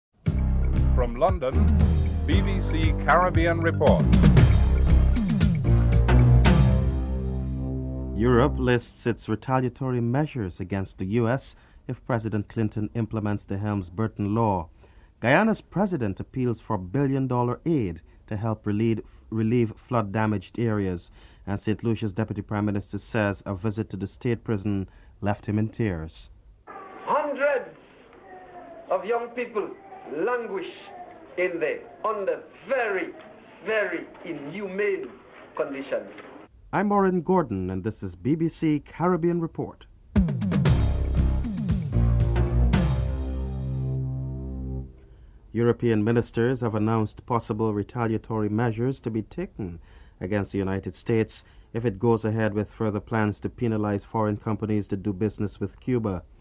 1. Headlines (00:00-00:43)
3. Guyana's President appeals for a billion dollars aid to help relieve flood damage areas. President Cheddi Jagan is interviewed (04:23-07:46)